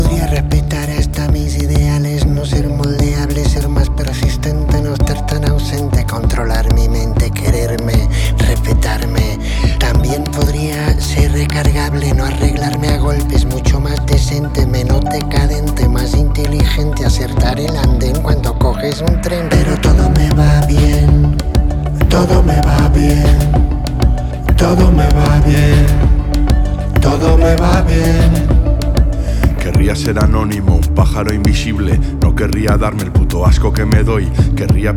Акустические струны и тёплый вокал
Жанр: Фолк